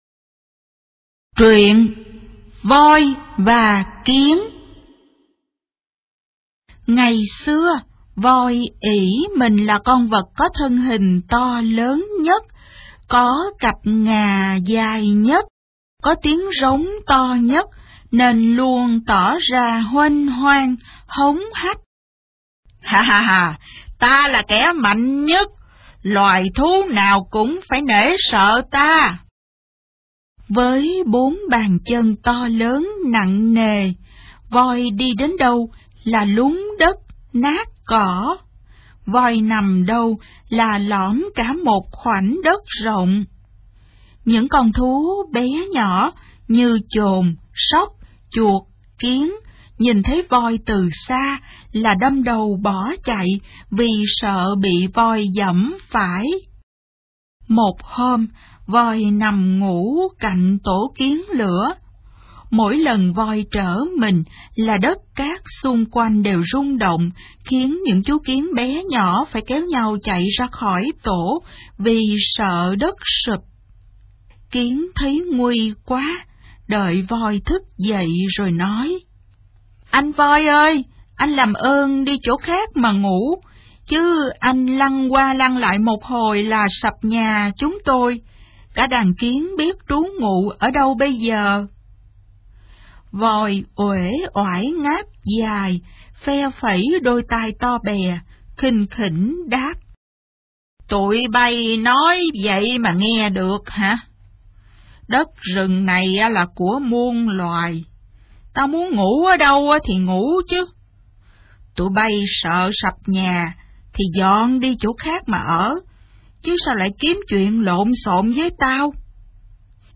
Sách nói | Truyện cổ tích. 35